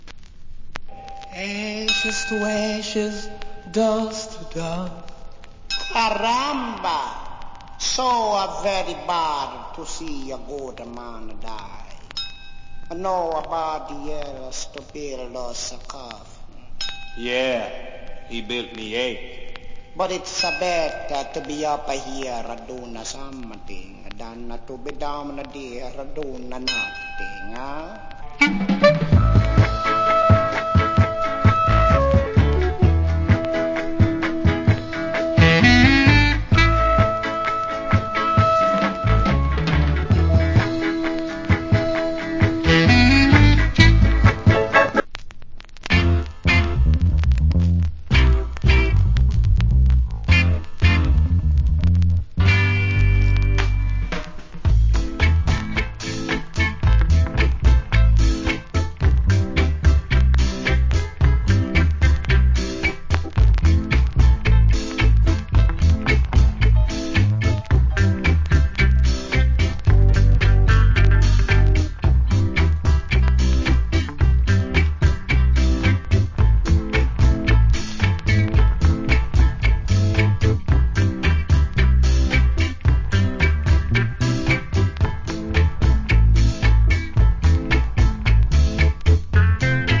Killer Reggae Inst.